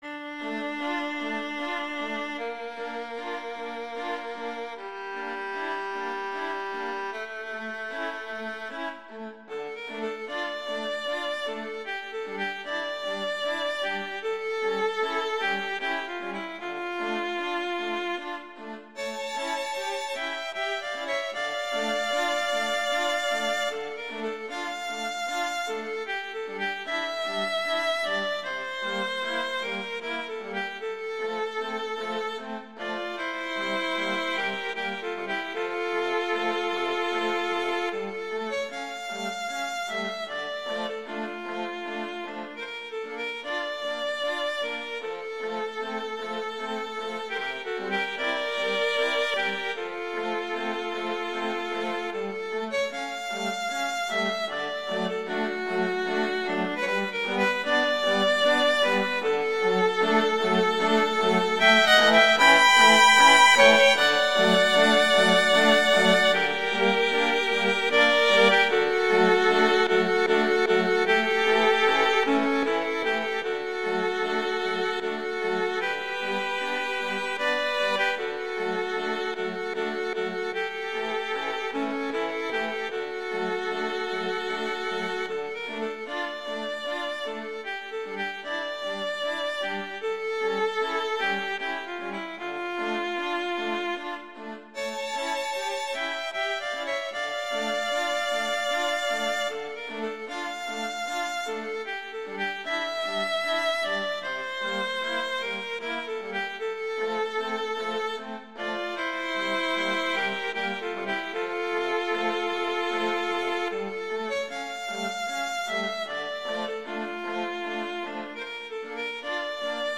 classical
D minor
♩=76 BPM